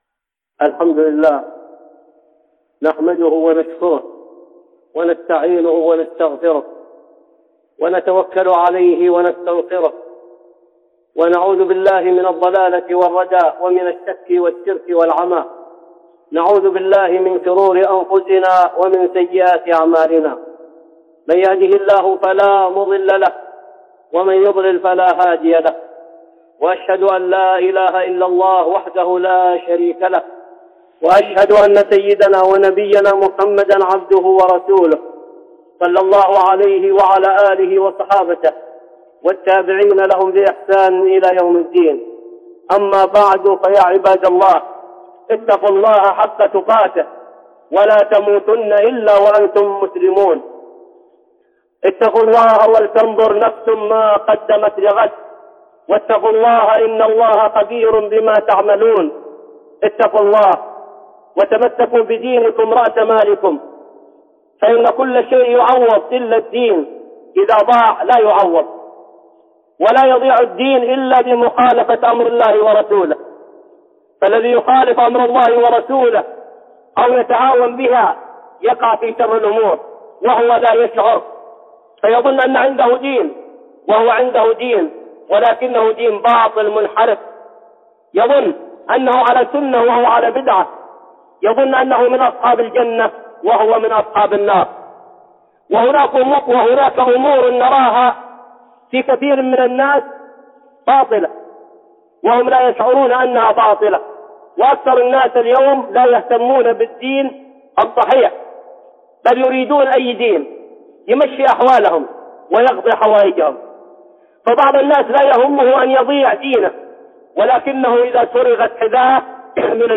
(خطبة جمعة) منكرات مخالفه للشرع